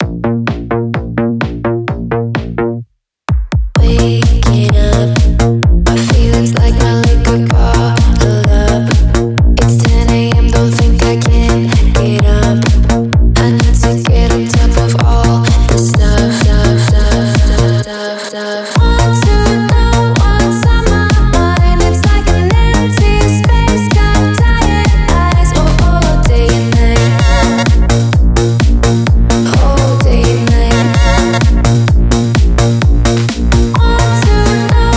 # Танцевальная